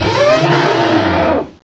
pokeemerald / sound / direct_sound_samples / cries / mamoswine.aif